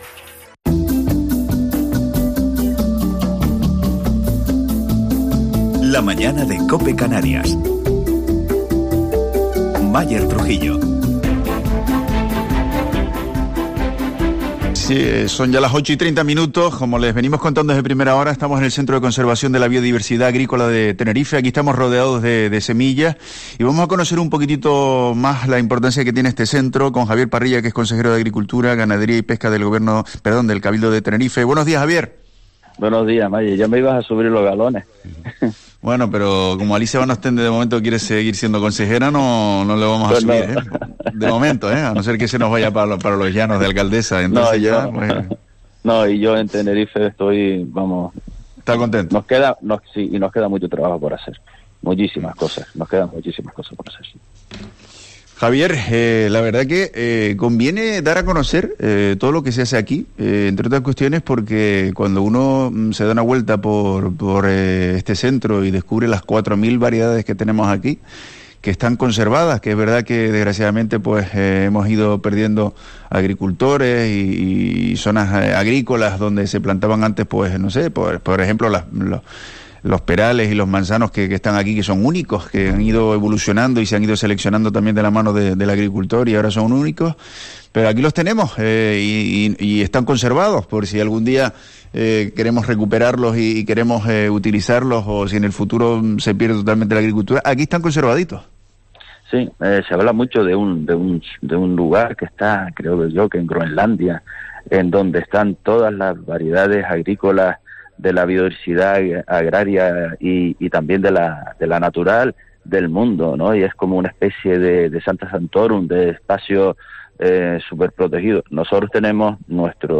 Entrevista a Javier Parrilla, consejero de Agricultura del Cabildo de Tenerife - CCBAT